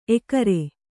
♪ ekare